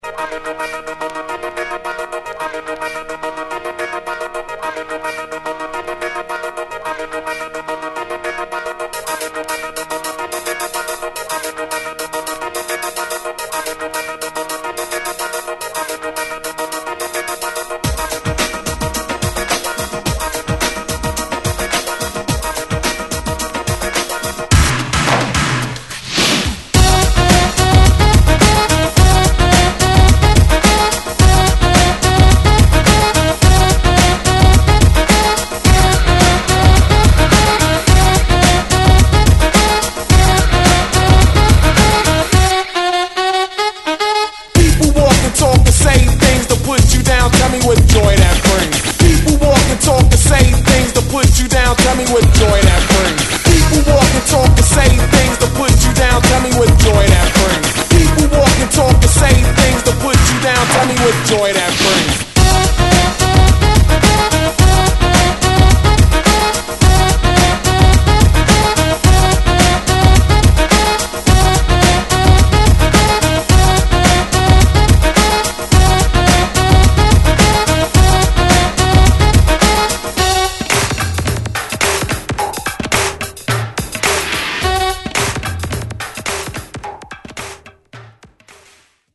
・ 45's SOUL / FUNK / DISCO / JAZZ / ROCK